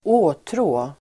Uttal: [²'å:trå:]